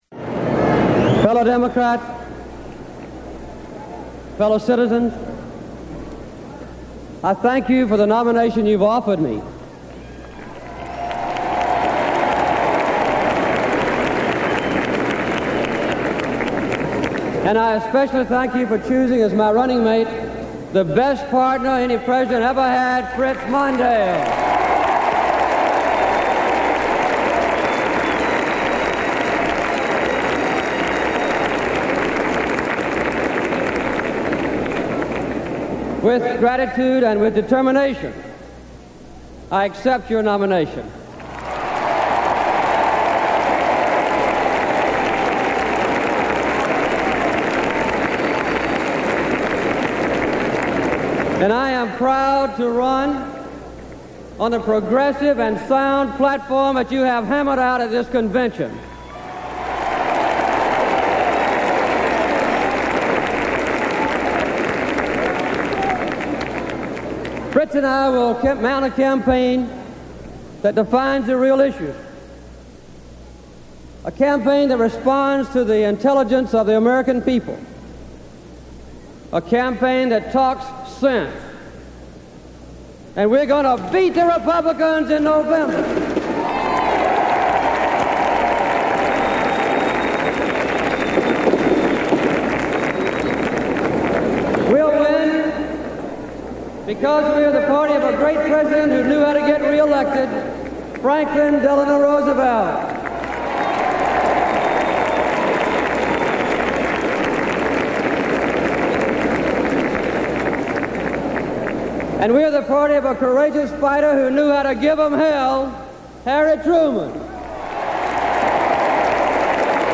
Jimmy Carter accepts the Democratic nomination at the 1980 Democratic National Convention